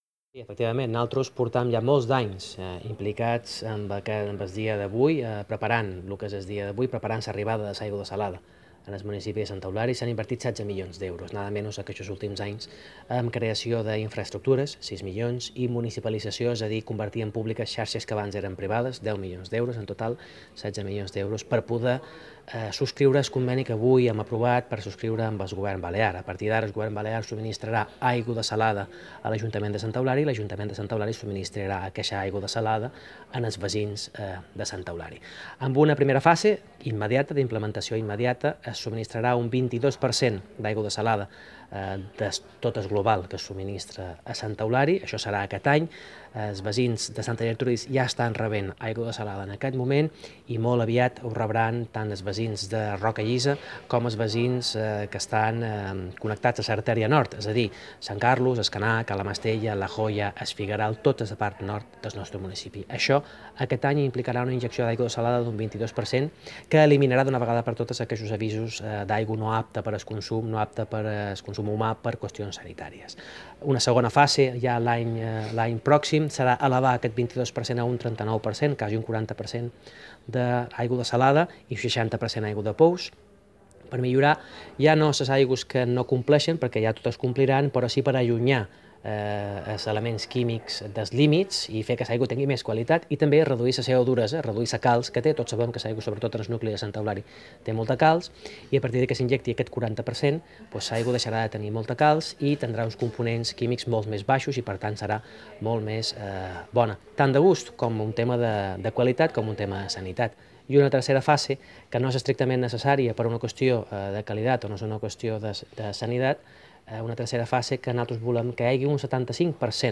Agua desalada- corte de voz Mariano Juan